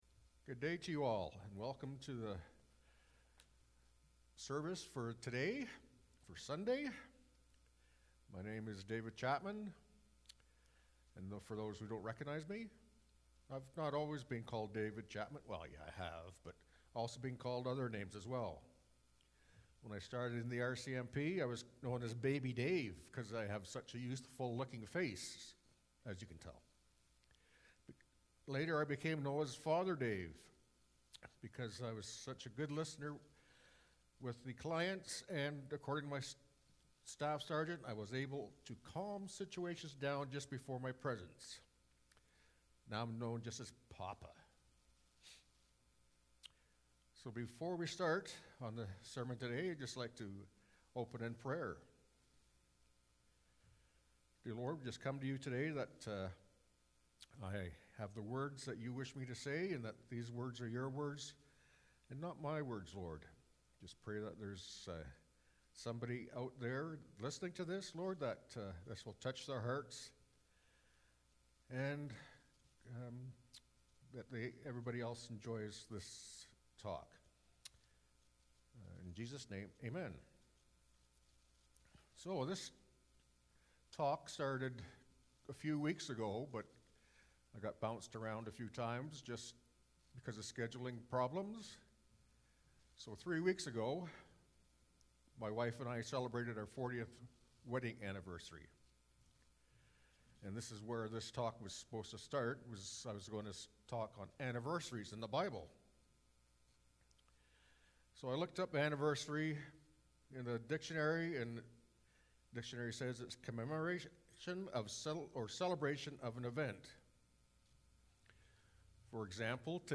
sermon-audio-may-9-2021.mp3